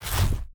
harness_unequip.ogg